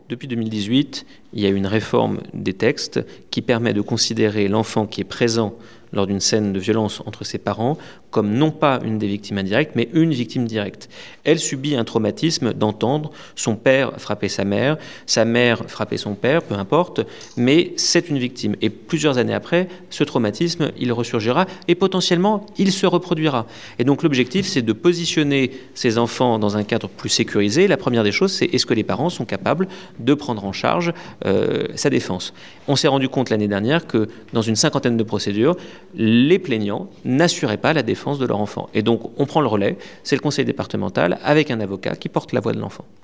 « Une délinquance maîtrisée » c’est avec ces mots que le préfet Gilles Quénéhervé a commencé sa prise de paroles face à la presse, jeudi dernier, pour tirer le bilan de la sécurité en Lozère en 2024.
Pour cela, le tribunal de Mende possède désormais un arsenal de dispositif plus complet pour répondre au mieux à ce fléau. Valéry Morron, procureur de la république.